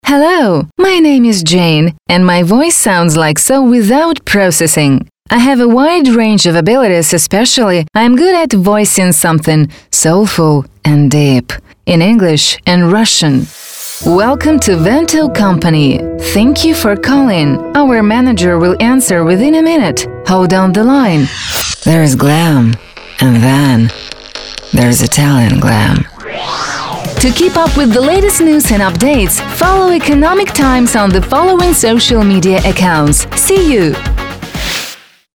Тракт: Конденсаторный микрофон SE ELECTRONICS SE X1 VOCAL PACK, USB-АУДИО ИНТЕРФЕЙС ROLAND QUAD-CAPTURE